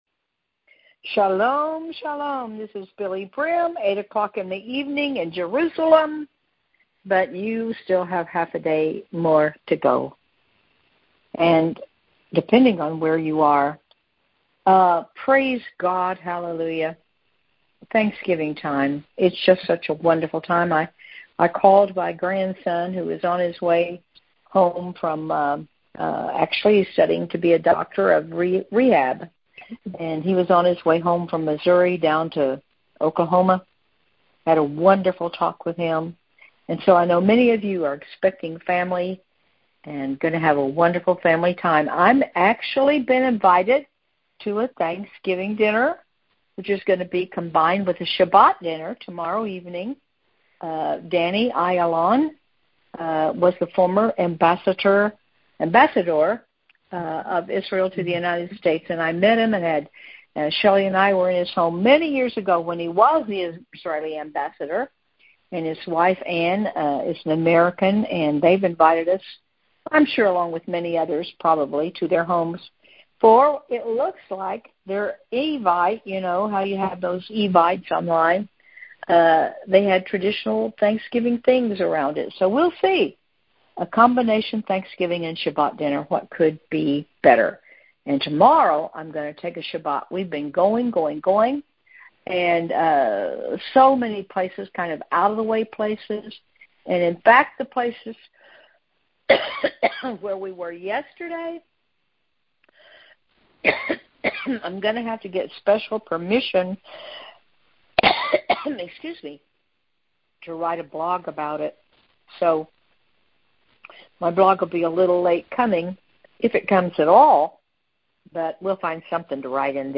The audio was recorded via our BBM Phone Cast system.